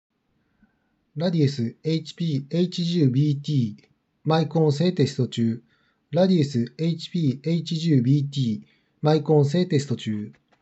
マイク性能はしっかりとした高さがある
少し重さはあるがしっかりと聞き取れるマイク性能。
✅「radius HP-H10BT」マイク性能